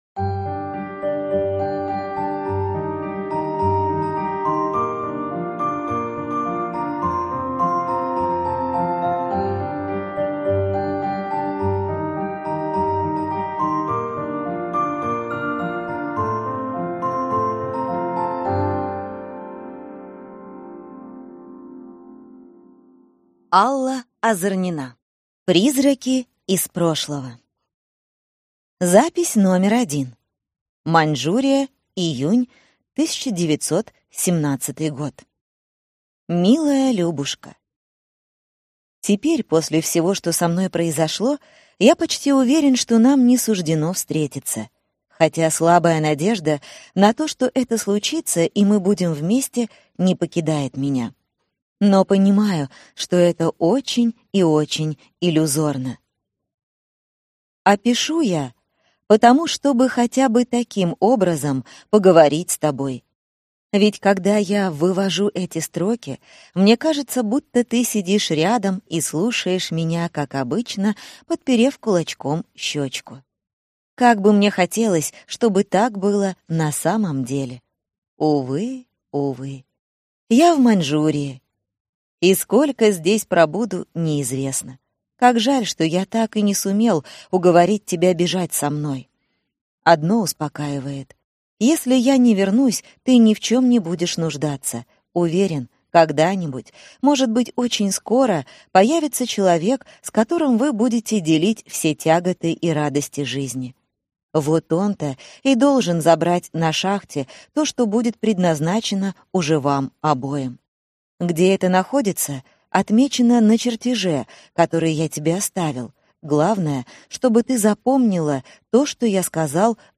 Аудиокнига Призраки из прошлого | Библиотека аудиокниг
Прослушать и бесплатно скачать фрагмент аудиокниги